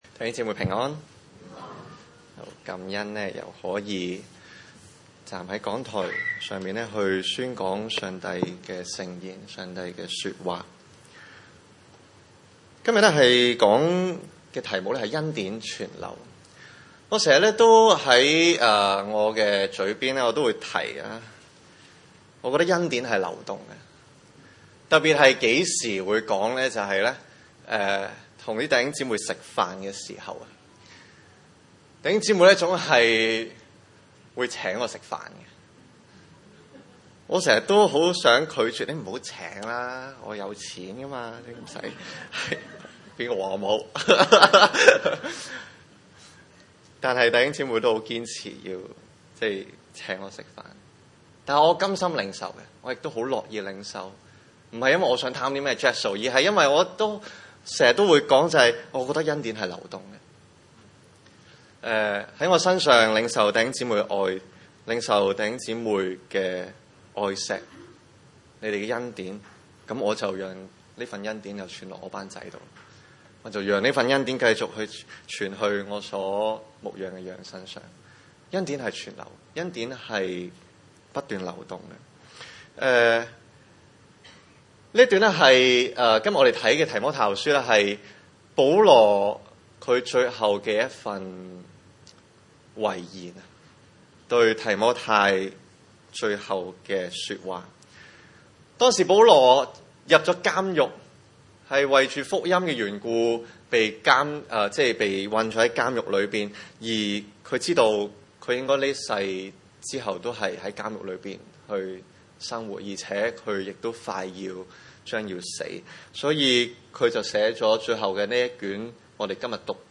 經文: 提摩太後書2︰1-13節 崇拜類別: 主日午堂崇拜 1.